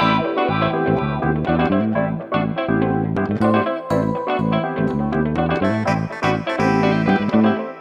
08 Backing PT4.wav